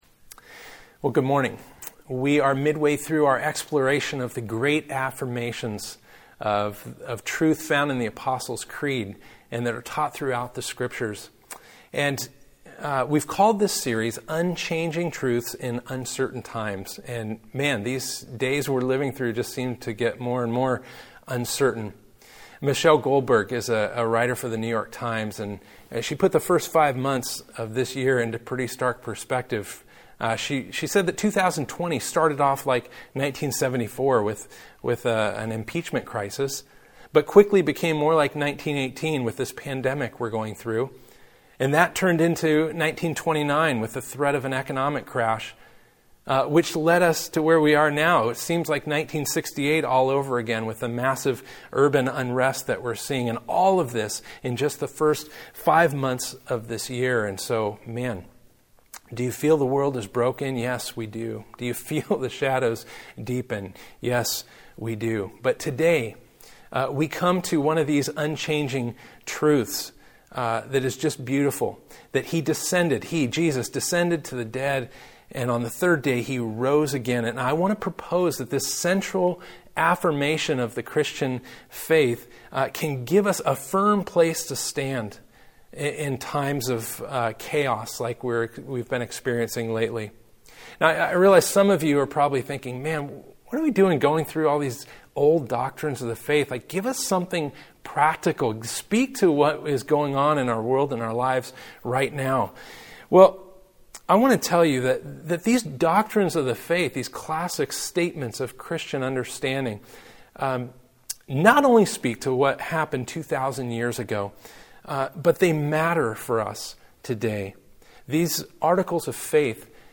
Apostles' Creed Passage: Romans 6.3-13 Service Type: Sunday Topics